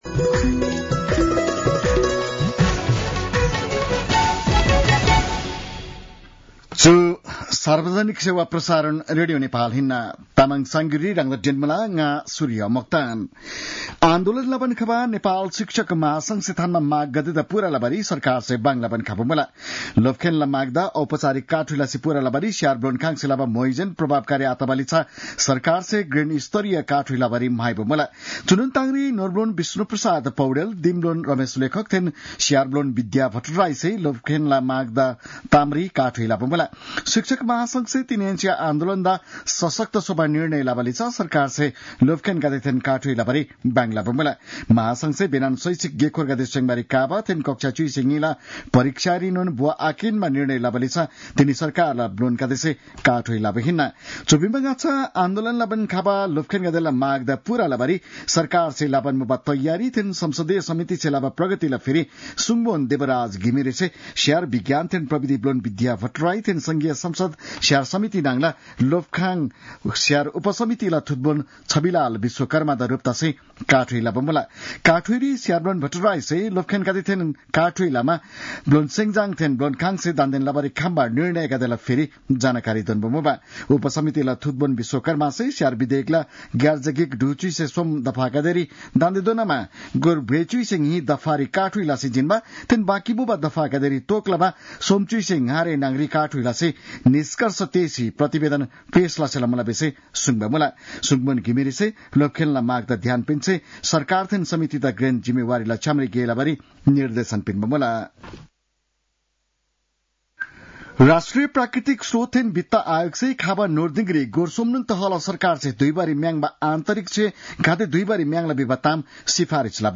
तामाङ भाषाको समाचार : २ वैशाख , २०८२